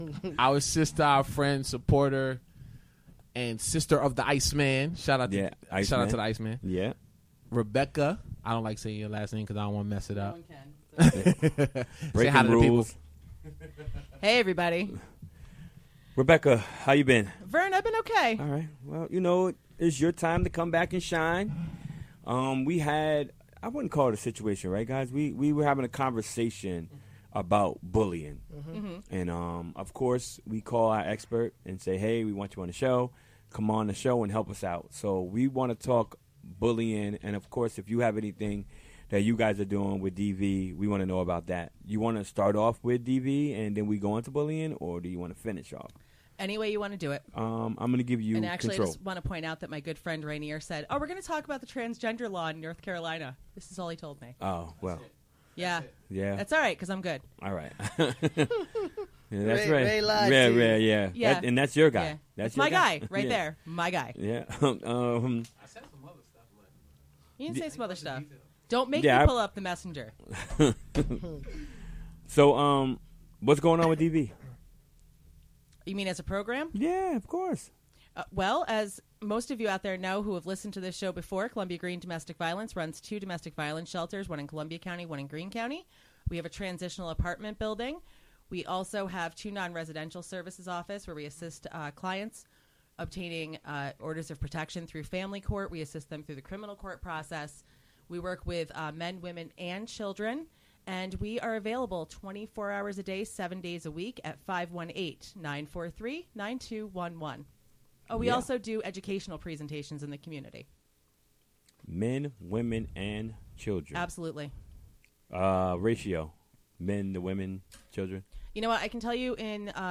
Recorded during the WGXC Afternoon Show.